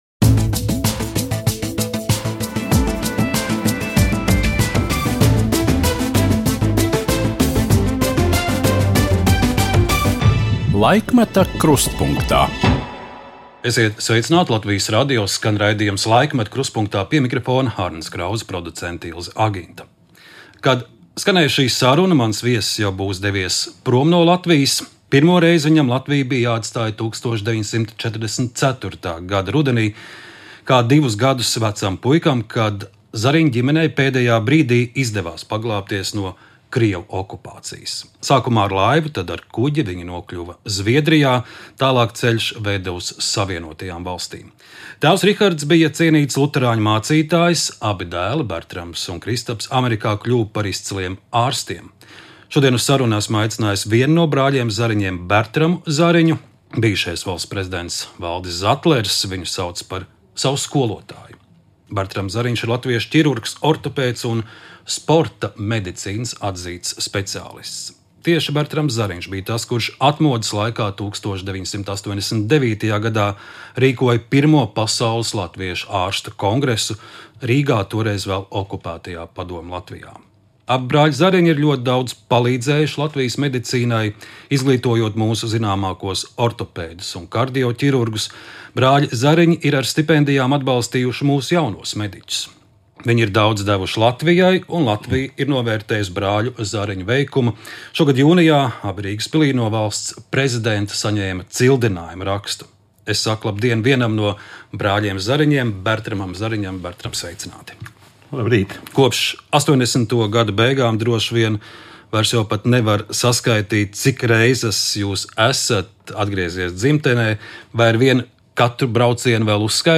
Saruna ar cilvēku, kuras dzimtai ir stipras saknes Latgalē.